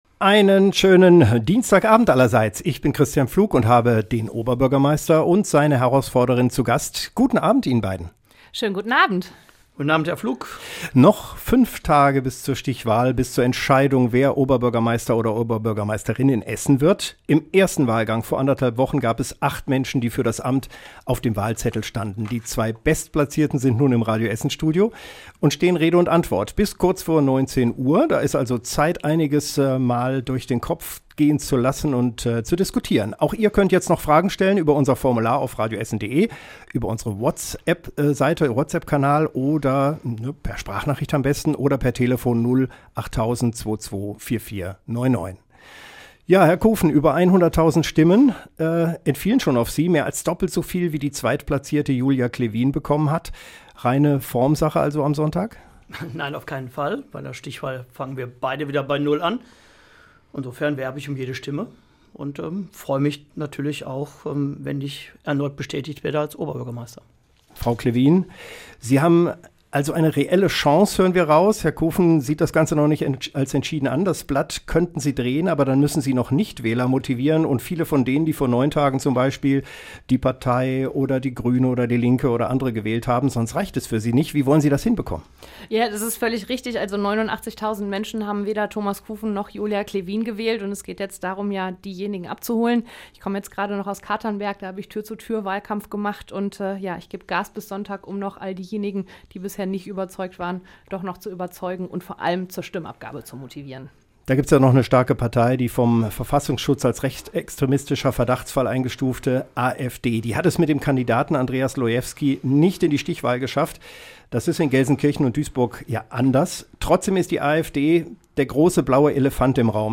Im OB-Duell bei Radio Essen diskutierten Thomas Kufen (CDU) und Julia Klewin (SPD) über Sicherheit, Schulen, Verkehr und viele weitere Themen.